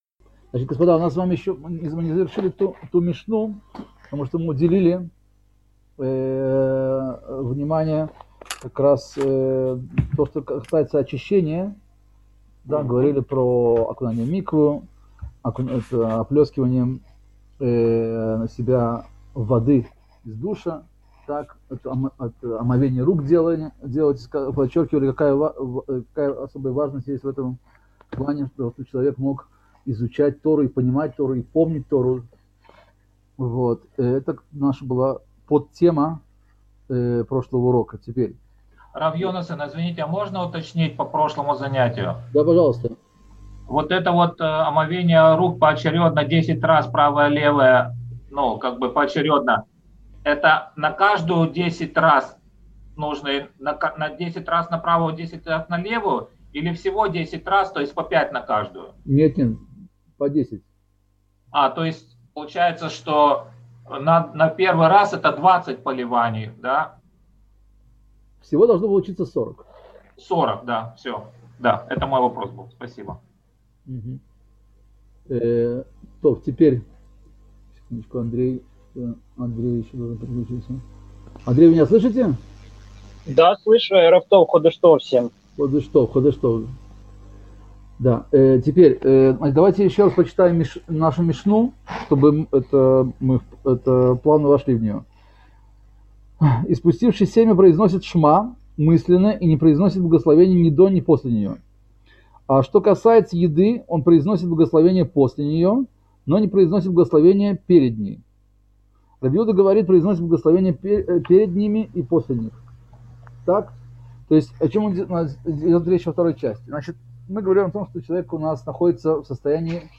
Цикл уроков по изучению мишны Брахот